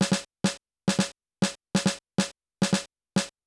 DRY LOFI S-R.wav